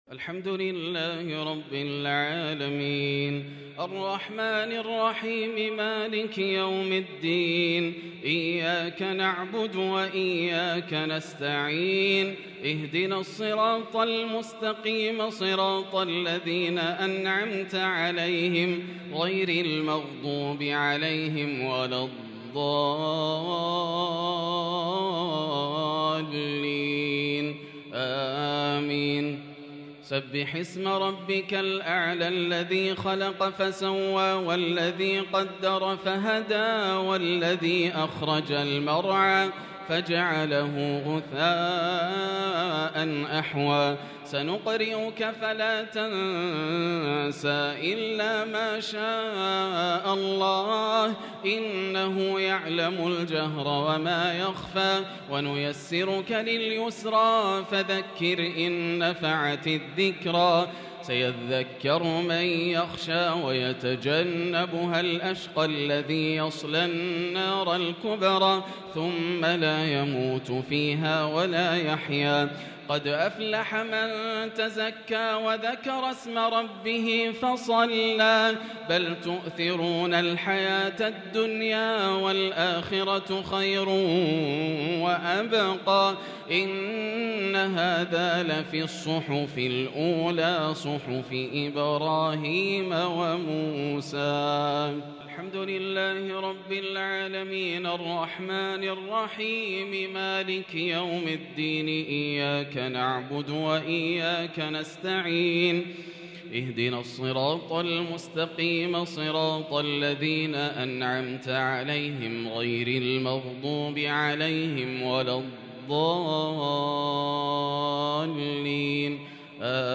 الشفع والوتر ليلة 5 رمضان 1443هـ | Witr 5 st night Ramadan 1443H > تراويح الحرم المكي عام 1443 🕋 > التراويح - تلاوات الحرمين